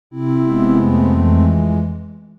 SE（敗北）
敗北などのSEです。